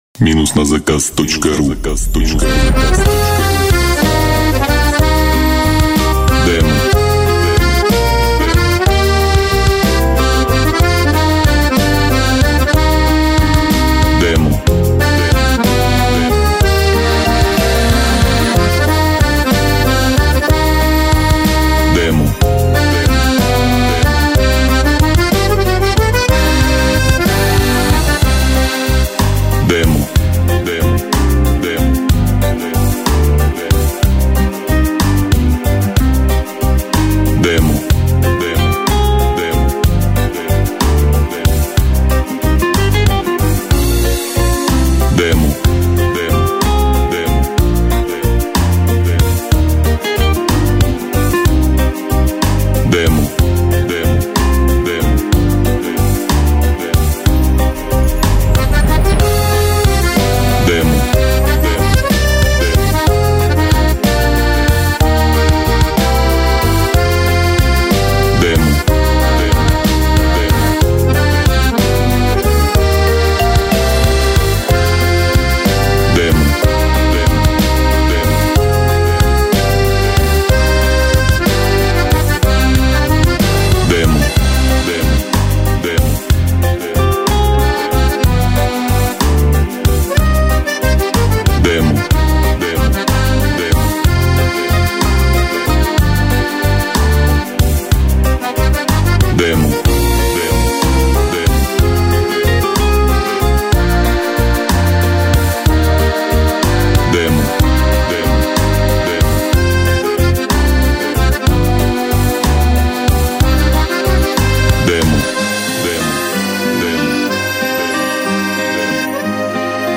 минус недорого